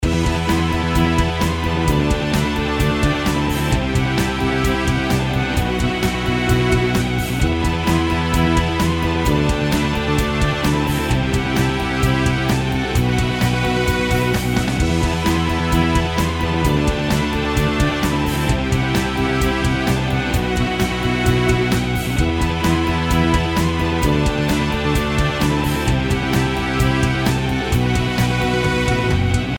音楽ジャンル： メタル
LOOP推奨： LOOP推奨
楽曲の曲調： HARD